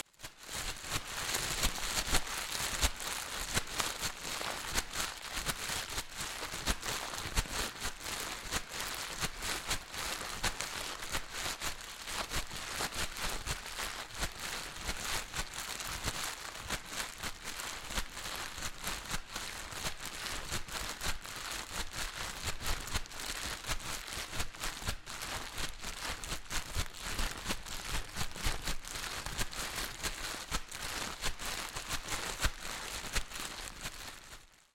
描述：来自垃圾尖端部位的再生吉他 音调向下移动 用作创造气氛，声音效果或新样品等的原材料
标签： 吉他 效果 价格便宜 抽象 soundesign 撞击 声音
声道立体声